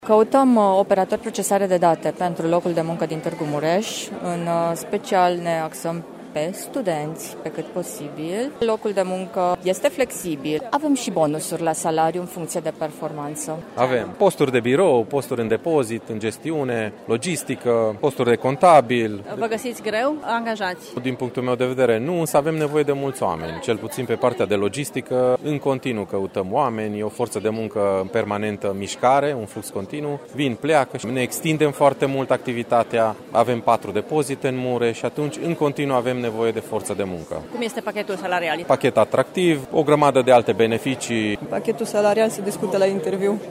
Angajatorii spun că pachetul salarial este atractiv, dar recunosc că au mișcări de personal: